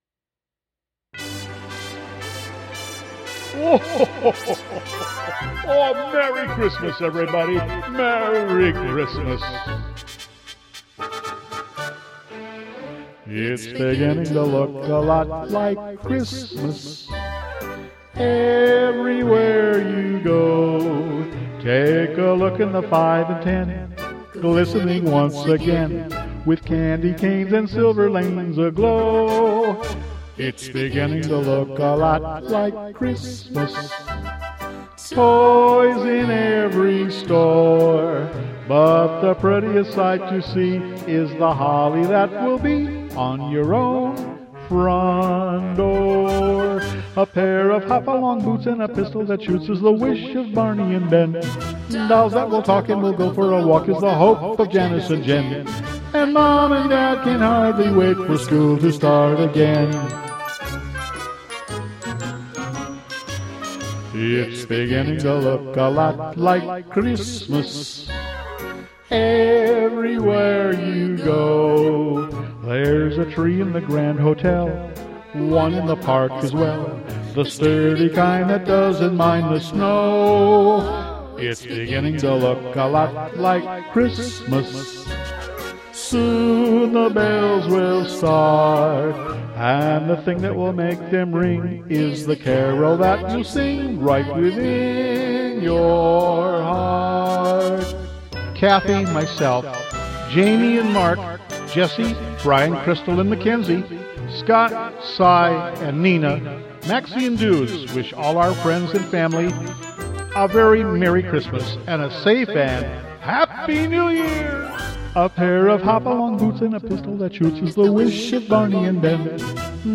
My Christmas Music covers